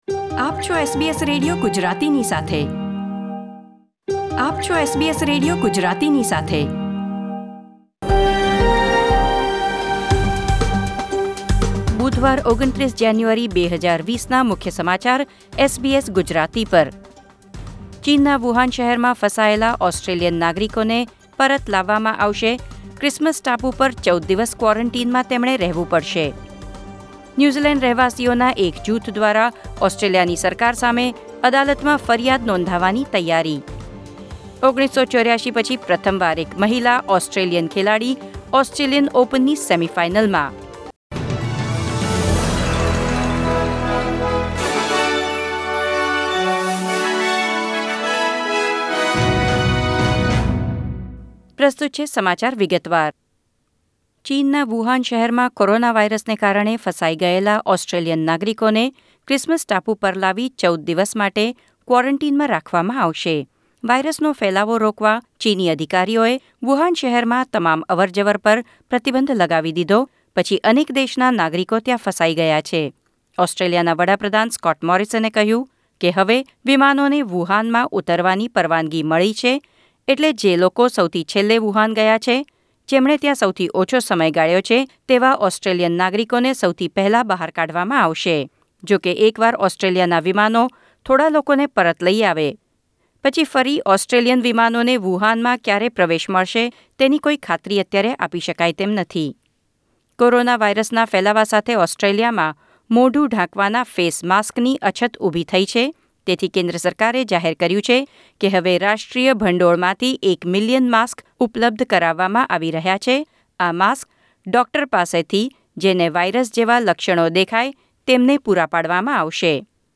૨૯ જાન્યુઆરી ૨૦૨૦ના મુખ્ય સમાચાર